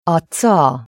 Affricate e articolazioni doppie